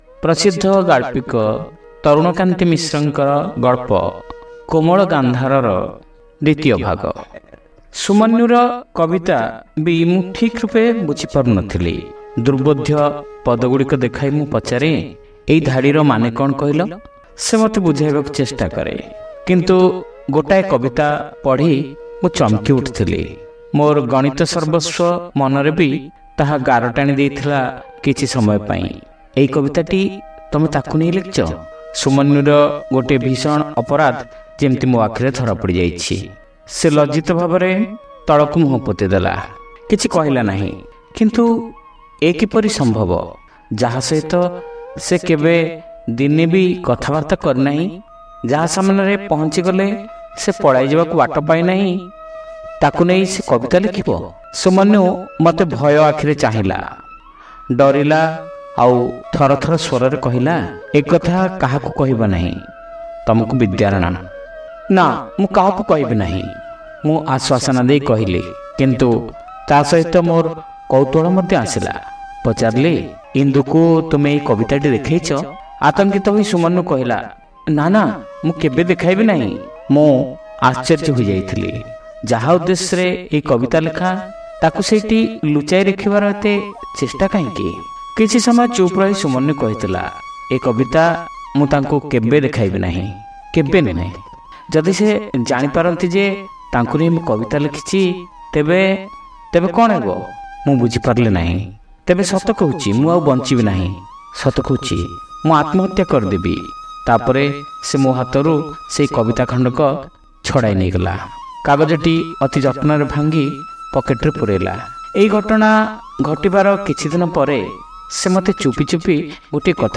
ଶ୍ରାବ୍ୟ ଗଳ୍ପ : କୋମଳ ଗାନ୍ଧାର (ଦ୍ୱିତୀୟ ଭାଗ)